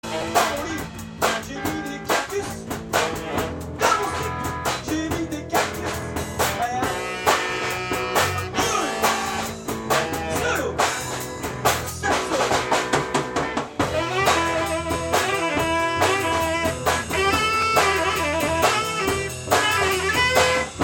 Enregistrement mini-disc (29.12.2001)